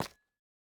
Minecraft Version Minecraft Version 21w07a Latest Release | Latest Snapshot 21w07a / assets / minecraft / sounds / block / calcite / step4.ogg Compare With Compare With Latest Release | Latest Snapshot
step4.ogg